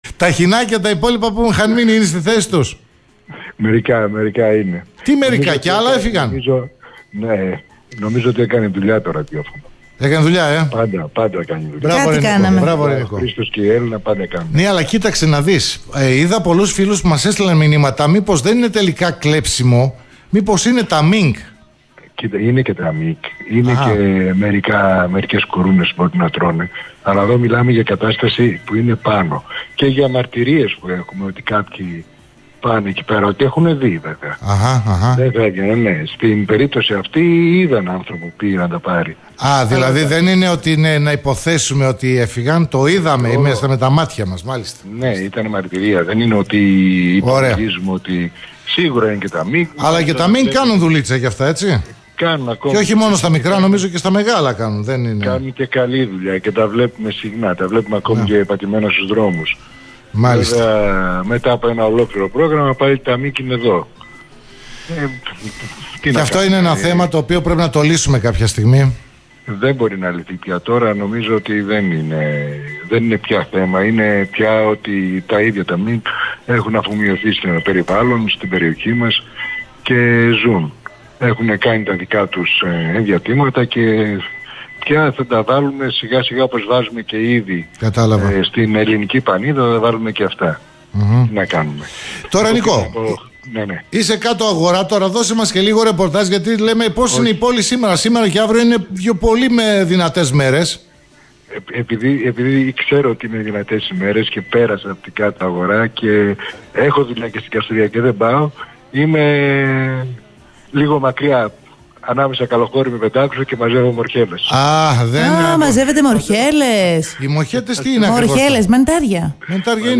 αλλά και για τον Σκορπιό (συνέντευξη)